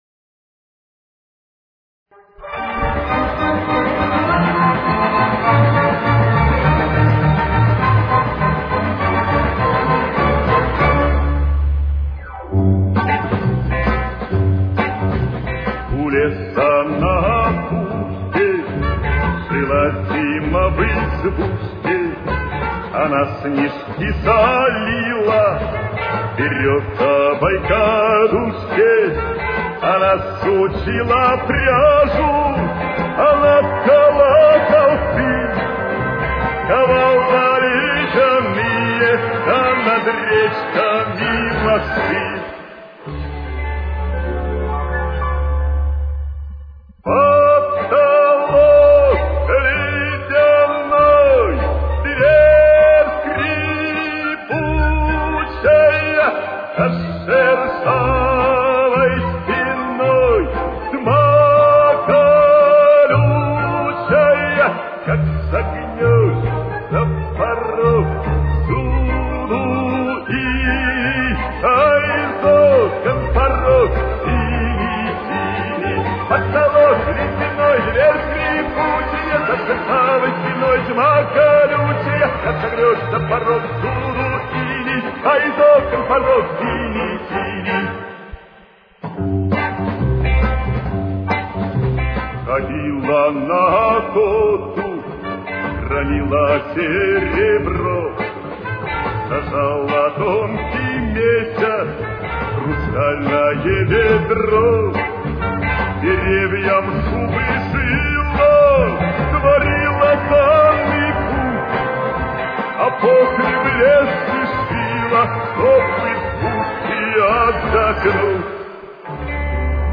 Темп: 73.